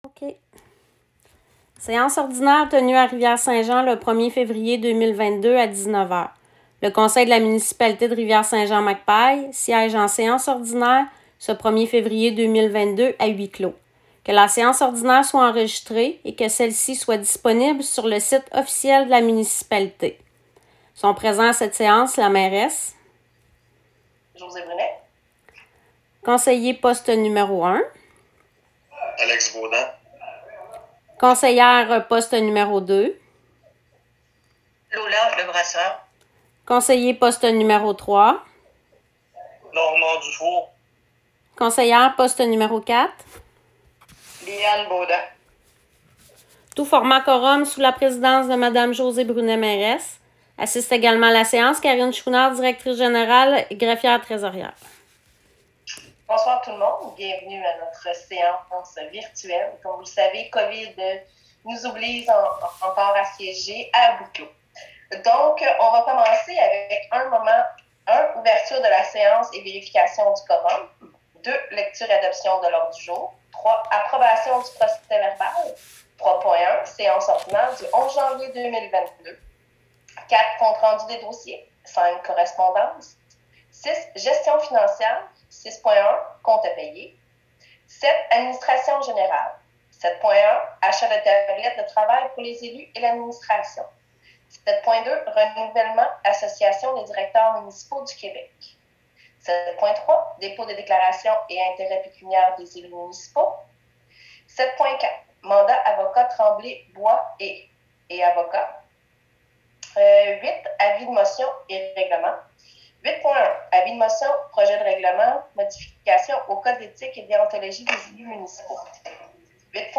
2022-02-01-Enregistrement-Séance ordinaire-Février.m4a